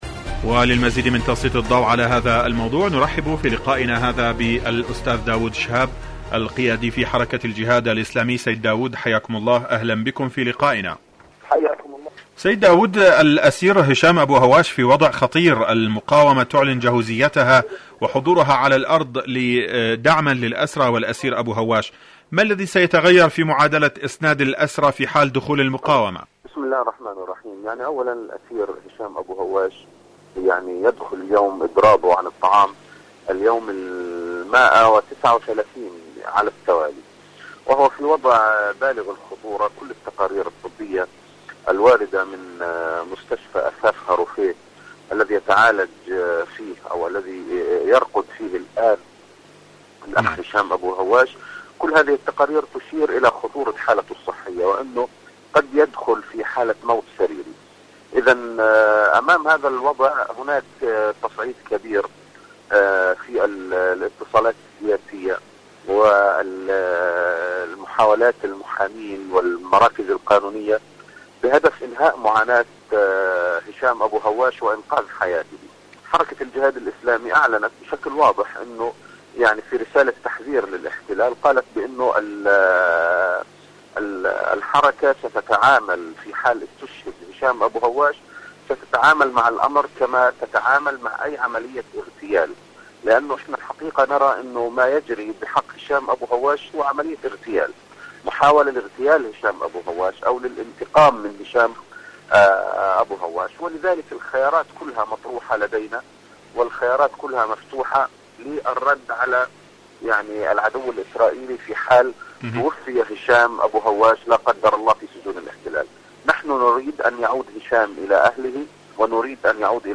مقابلة إذاعية